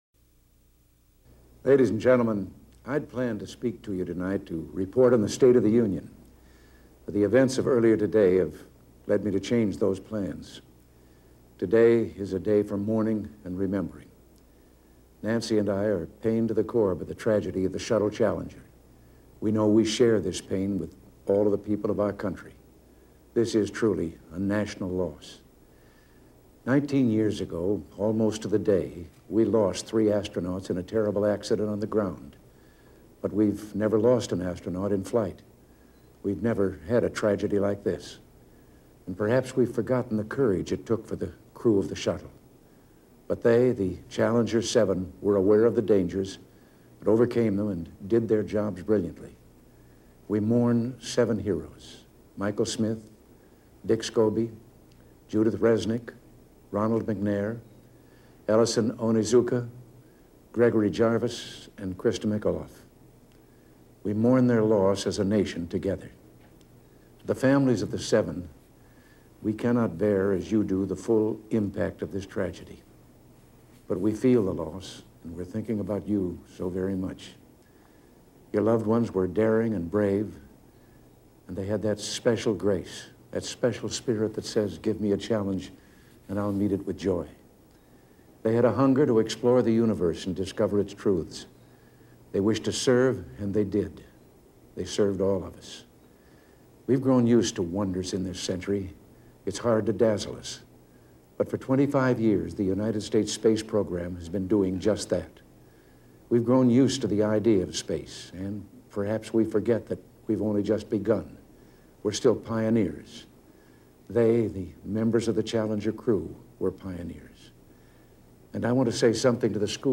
delivered 28 January 1986, Oval Officer, White House, Washington, D.C.
Audio mp3 of Address       AR-XE mp3 of Address
Audio Note: Audio Remastered 6/27/24.